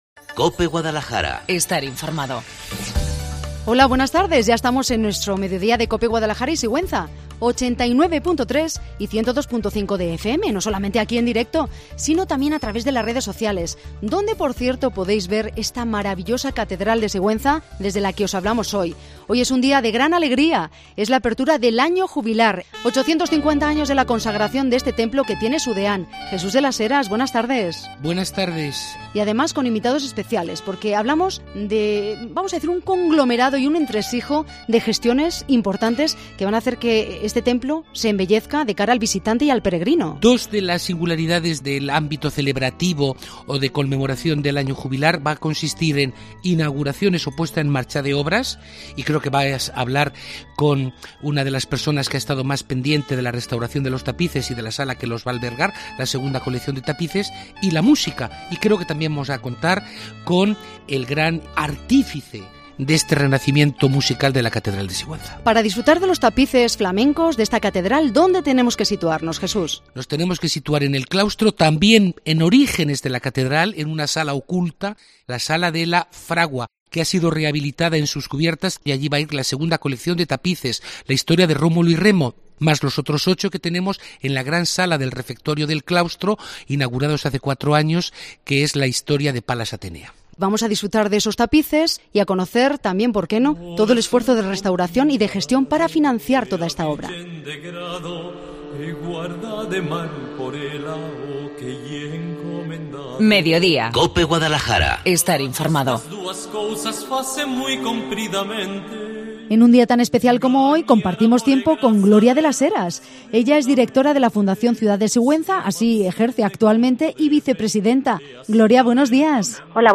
La Mañana en COPE en Guadalajara Cope Guadalajara se traslada a la Catedral de Sigüenza en la apertura del Año Jubilar 2ª Parte Hoy, 19 de junio, comienza la celebración del Año Jubilar que la Santa Sede ha concedido, por primera vez, a la Diócesis Sigüenza-Guadalajara, con motivo del 850 aniversario de la consagración de la Catedral seguntina. Hasta este templo catedralicio se han trasladado los micrófonos de Cope Guadalajara para conocer de boca de sus protagonistas todos los detalles de este ciclo de 366 días, repletos de actos litúrgicos, culturales y sociales.